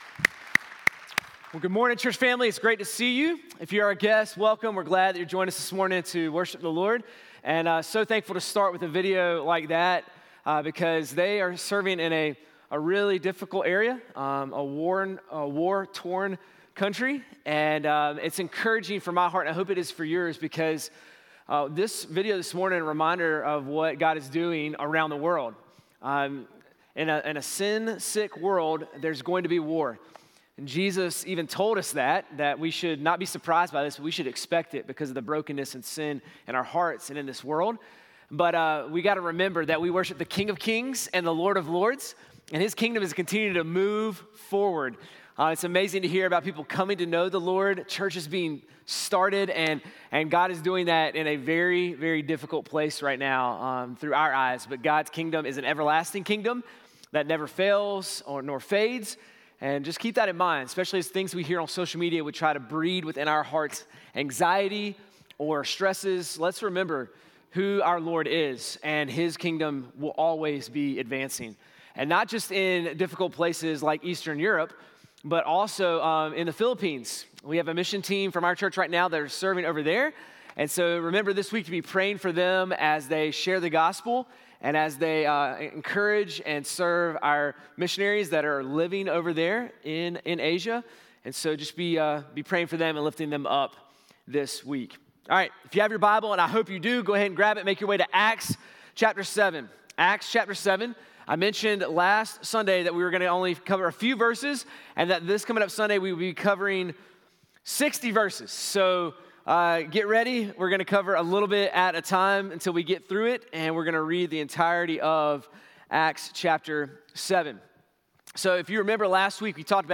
sermon-3-15-26.mp3